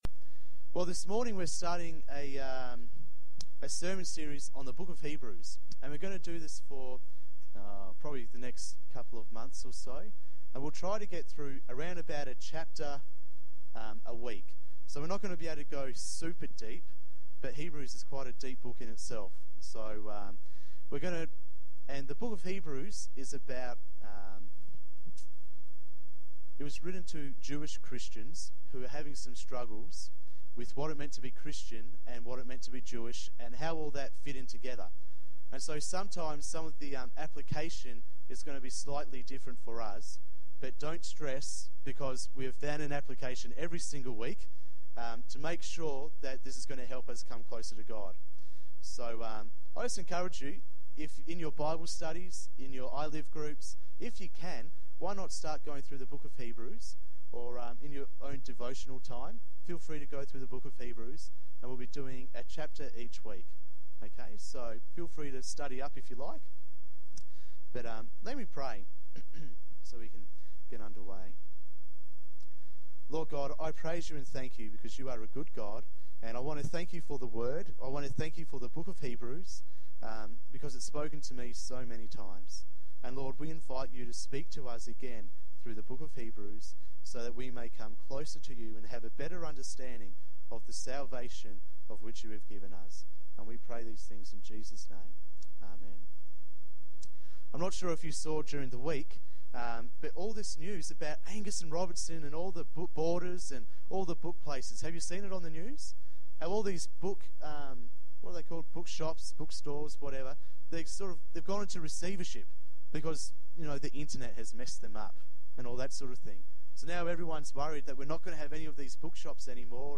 This is the first sermon in our series, "SUPERIOR" based on the Book of Hebrews.